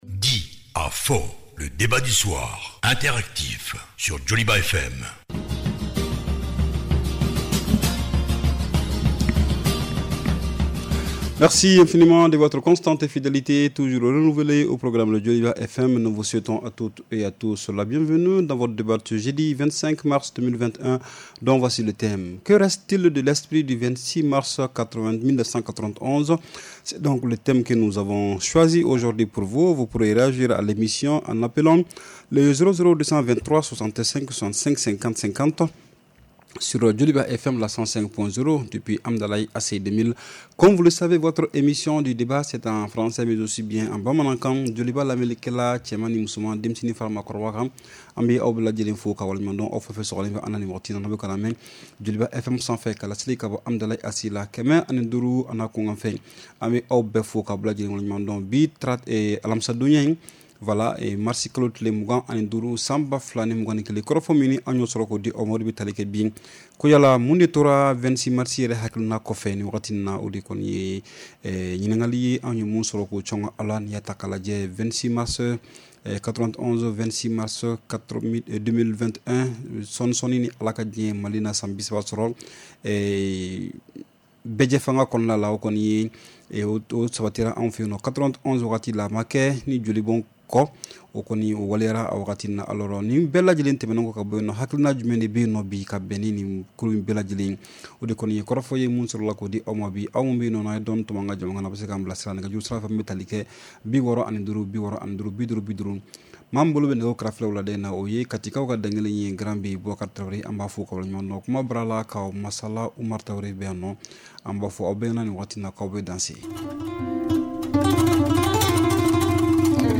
REPLAY 25/03 – « DIS ! » Le Débat Interactif du Soir